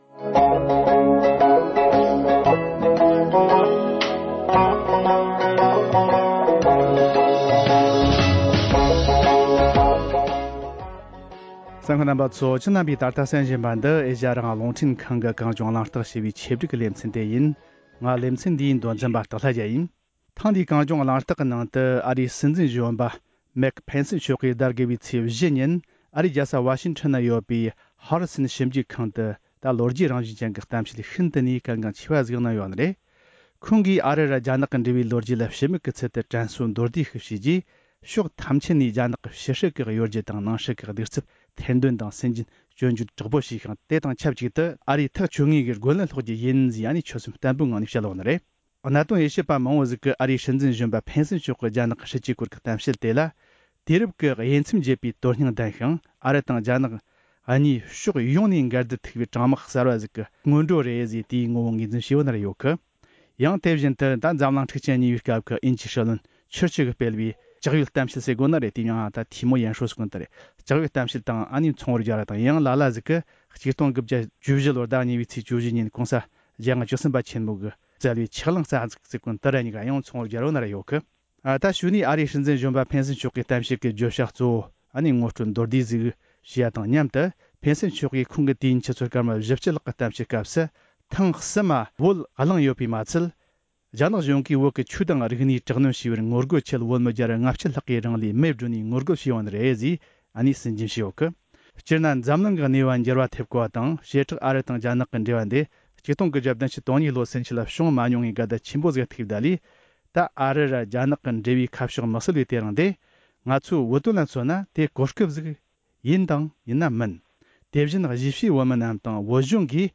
ཉེ་ལམ་ཨ་རིའི་སྲིད་འཛིན་གཞོན་པས་སྤེལ་བའི་རྒྱ་ནག་གི་སྲིད་ཇུས་ཐད་སྤེལ་བའི་གཏམ་བཤད་ཐད་མི་སྣ་ཁག་དང་ལྷན་དུ་བགྲོ་གླེང་ཞུས་པ།